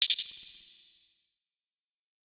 HHAT - SLOW4U.wav